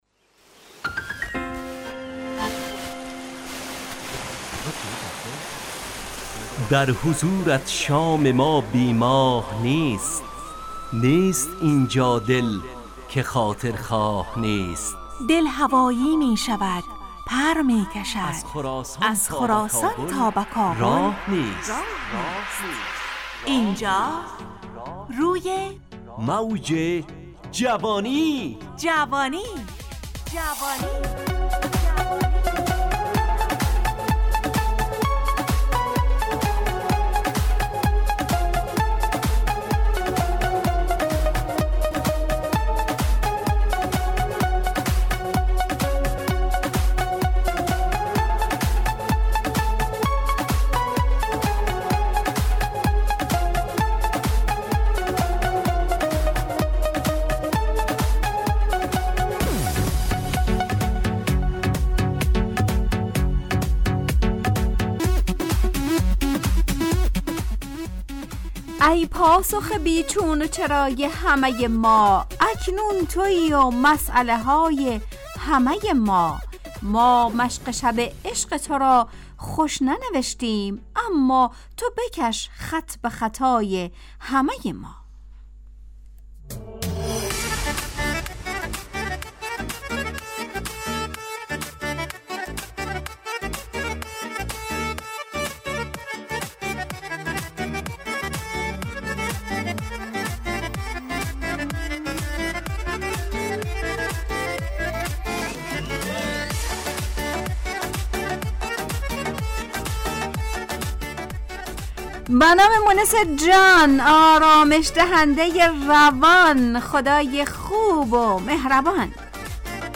همراه با ترانه و موسیقی مدت برنامه 70 دقیقه . بحث محوری این هفته (خوب و بد)
برنامه ای عصرانه و شاد